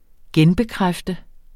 Udtale [ ˈgεn- ]